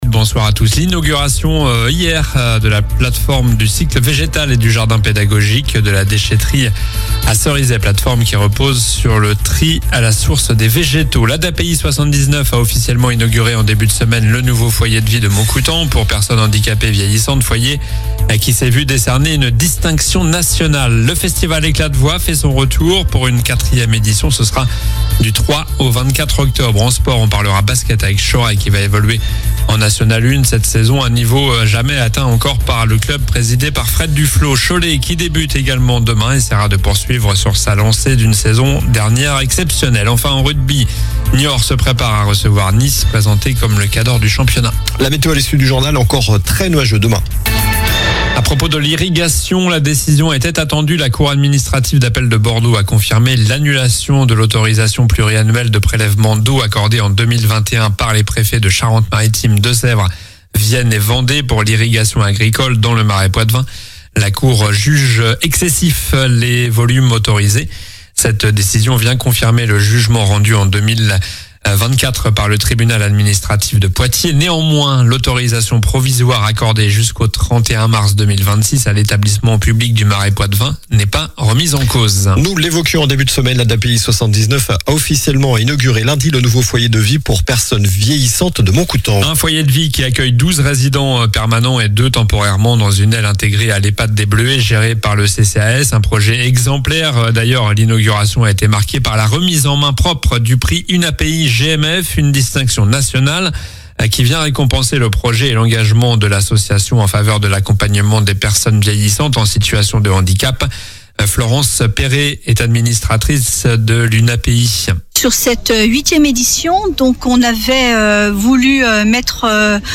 Journal du vendredi 26 septembre (soir)